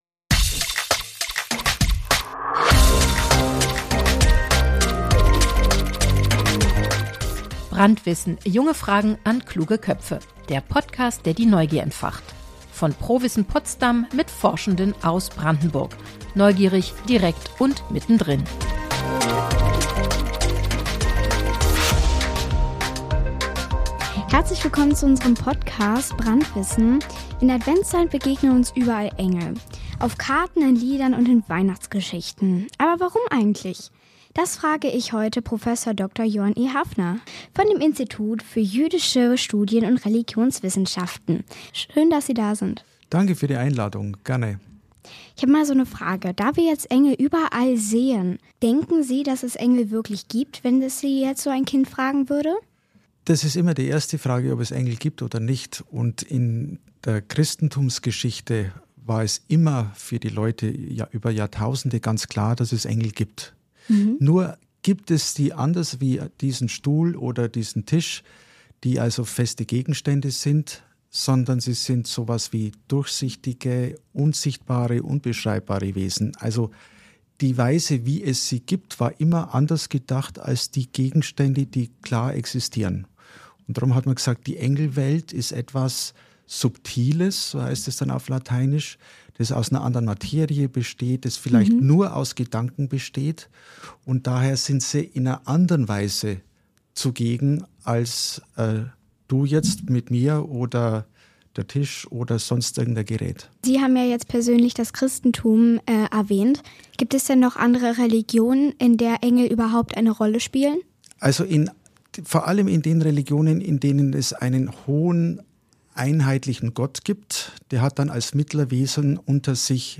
Wir erfahren, was Engel mit dem Wort „Fürchte dich nicht!“ verbinden, was Dschinn im Islam bedeuten, wie Menschen ihre eigenen Engelvorstellungen entwickeln – und warum Engel eine so faszinierende Rolle im Glaubensspektrum vieler Menschen spielen. Ein Gespräch über Glauben, Hoffnung und die Macht von Bildern und Geschichten – ganz besonders in der Adventszeit.